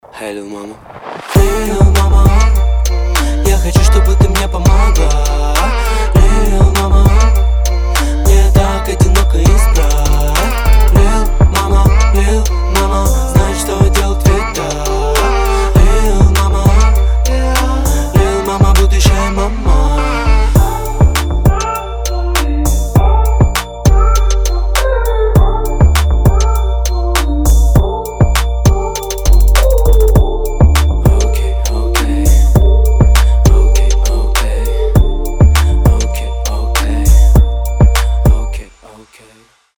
мужской вокал
Хип-хоп
мелодичные
качающие